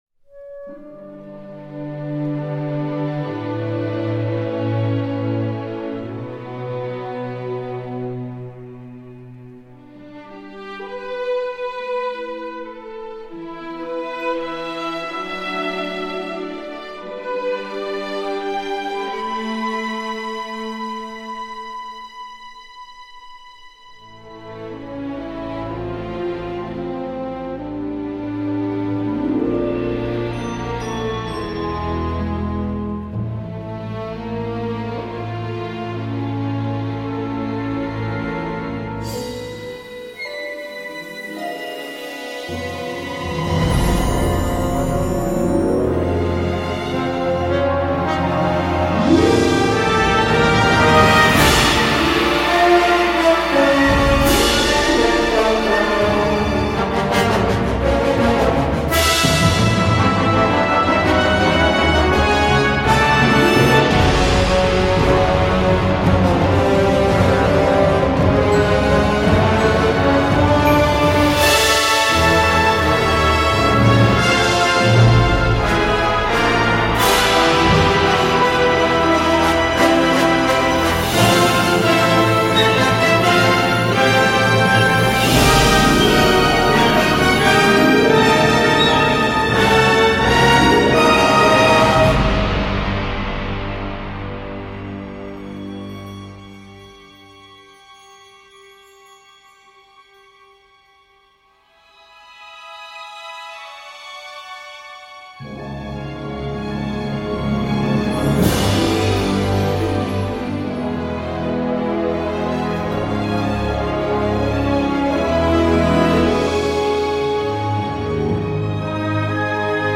score d’aventure symphonique à l’ancienne
Ça débute mysterioso
et ça continue avec virtuosité